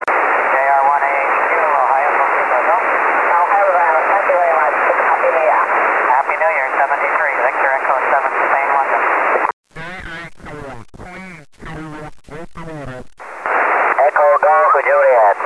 6m4(MP3 150KB)　　　SSBの　VE7　の様子。当局も呼びましたがからぶり。